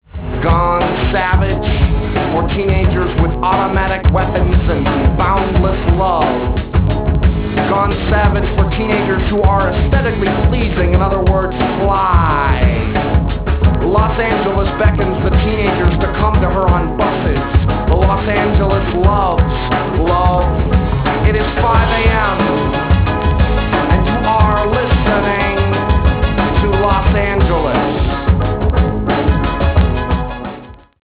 who plays the upright bass
who plays the drums
who plays keyboard sampler
plays the electric guitar
Funky drums. Acoustic bass. Skittery guitar.